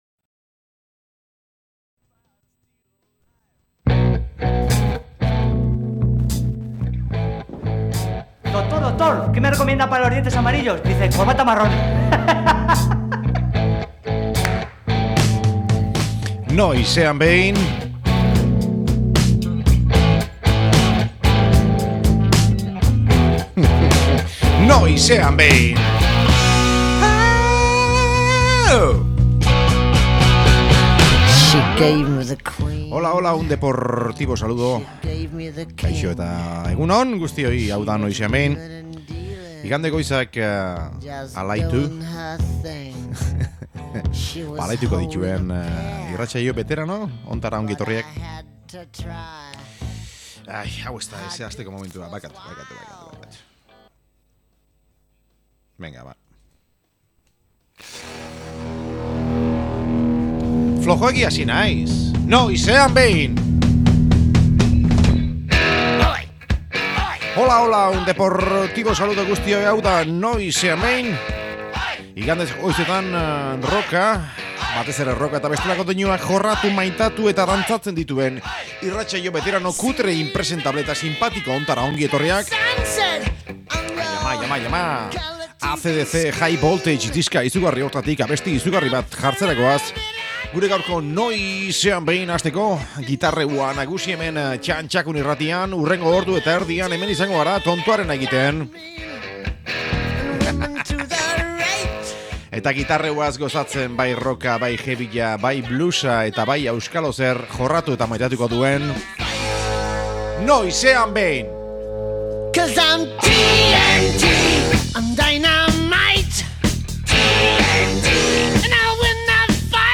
Blues pixka bat, heavy pixka bat gehiago eta azkeneko PATAPUM bat.